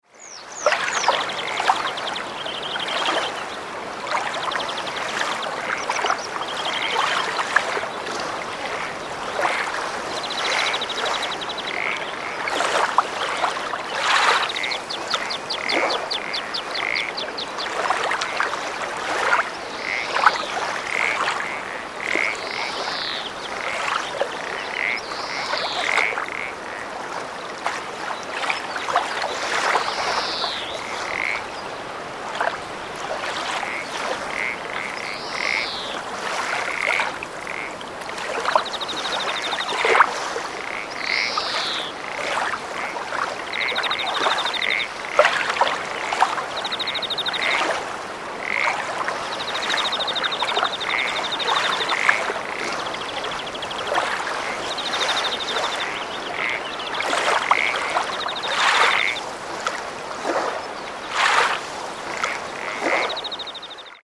Audio Auszug / Vorschau the beautiful sound of the lake: water, frogs, birds 06:26 min